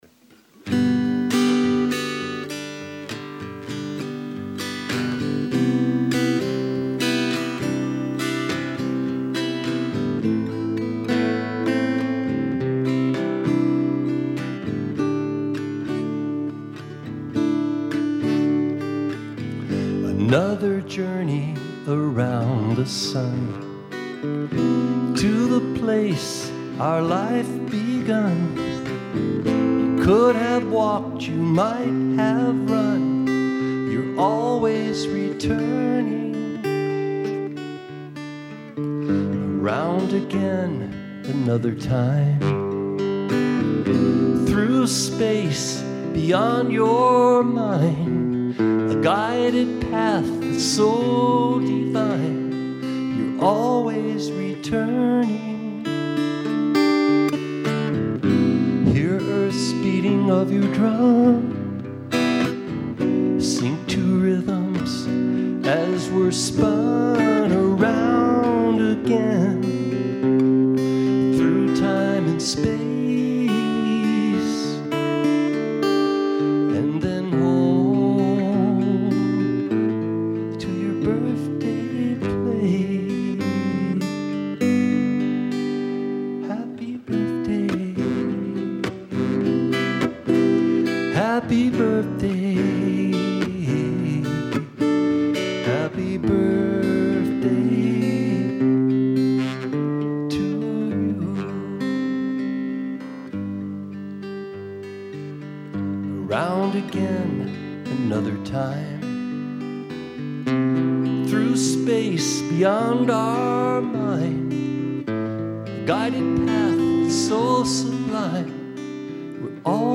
An original Happy Birthday Song.